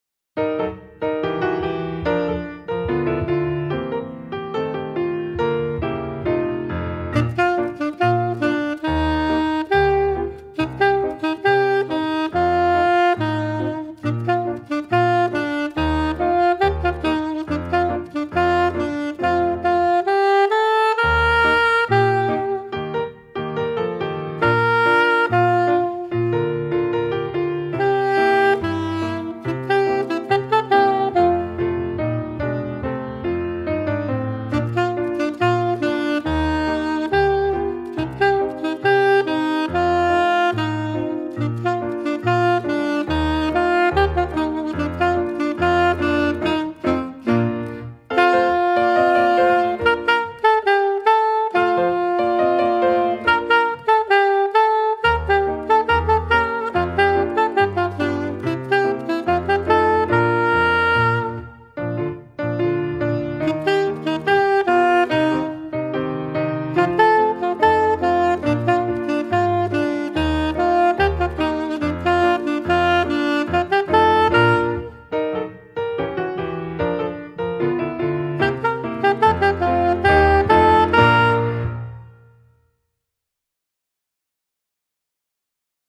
Voicing: Alto Saxophone Collection